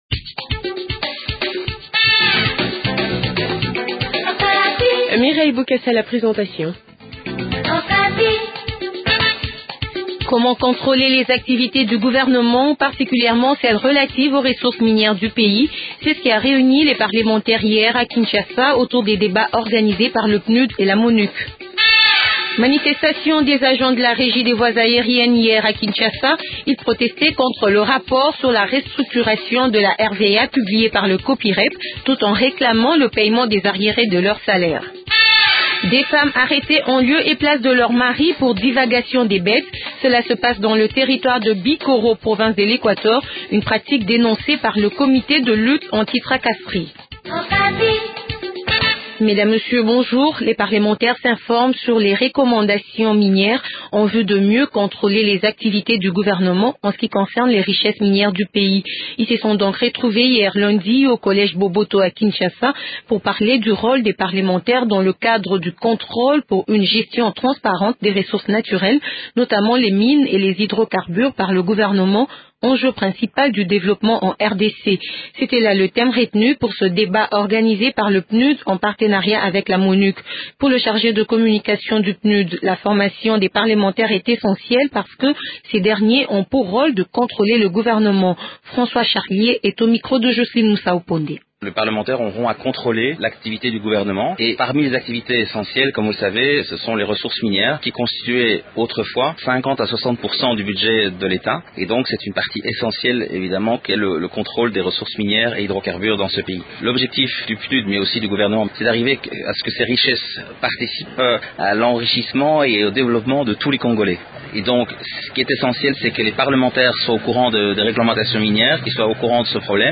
Dans ce journal , la réaction d’ un participant à ces débats organises par le Pnud et la Monuc. Titre 2 : Manifestation des agents de la RVA hier à Kinshasa. Ils réclament le paiement des arrierés de leurs salaires et rejettent catégoriquement le rapport sur la restructuration de la RVA.